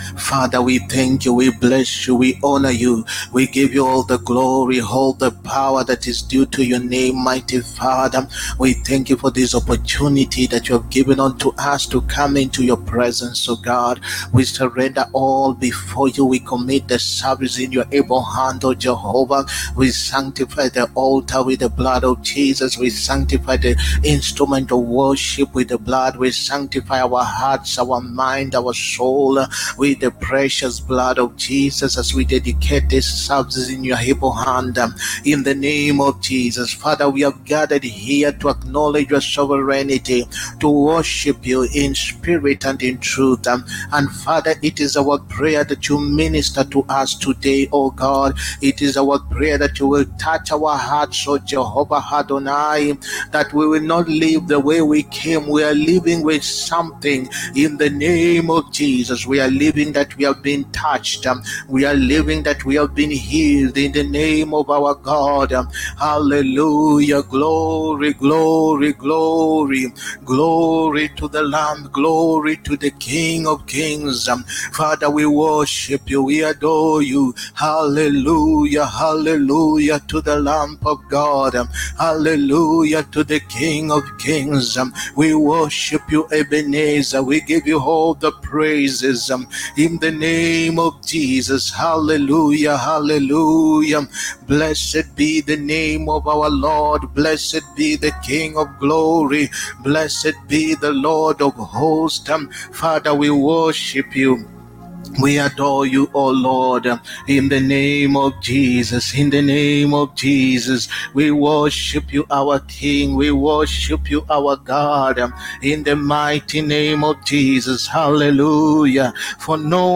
HEALING, PROPHETIC AND DELIVERANCE SERVICE. 10TH AUGUST 2024.